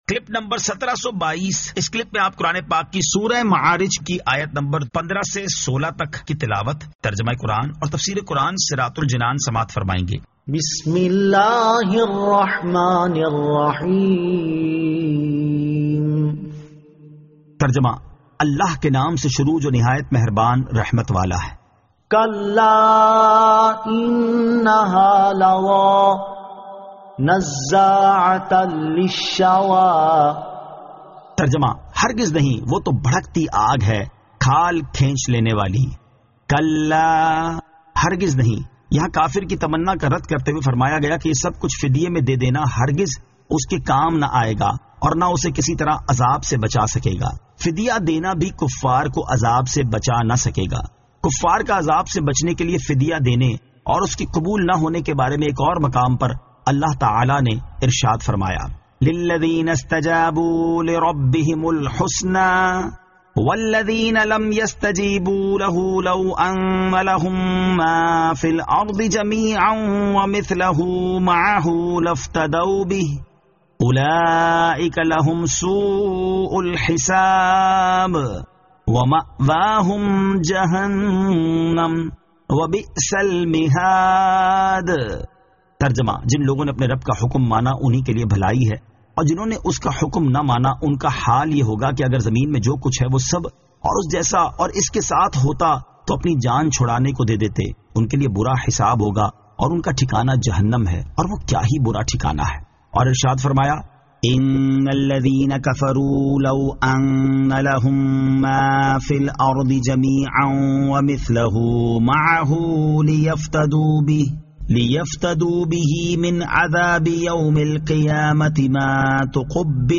Surah Al-Ma'arij 15 To 16 Tilawat , Tarjama , Tafseer